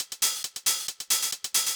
K-5 Hi Hats.wav